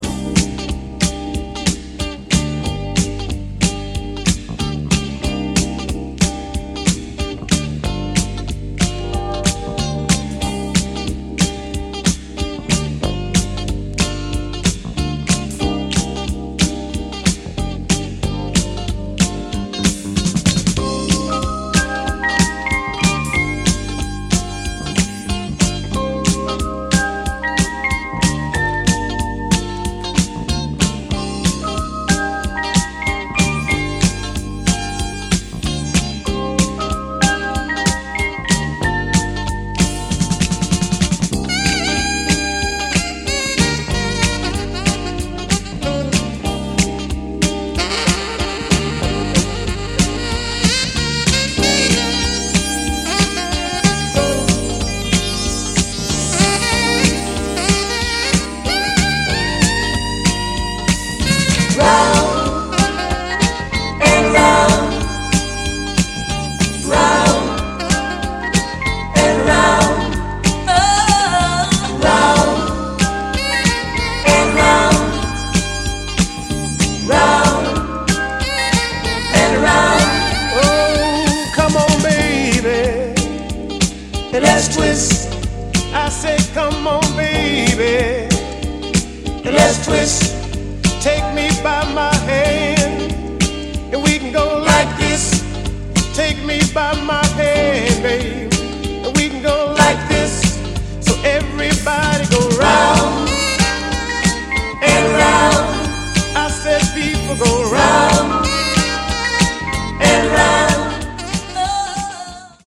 Funky club hit to get down to, whenever whyever!